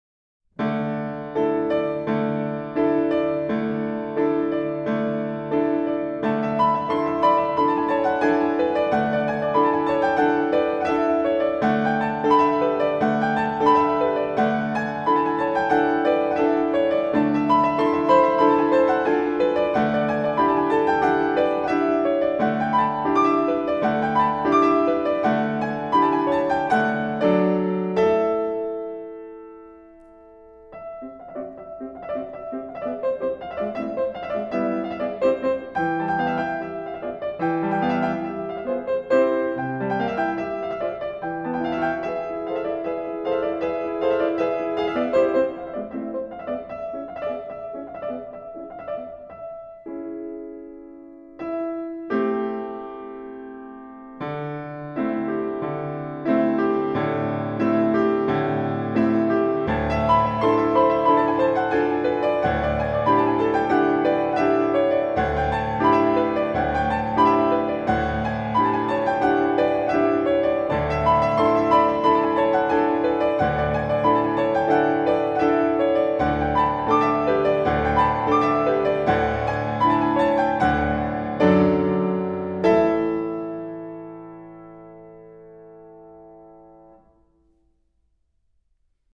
Sonatina para piano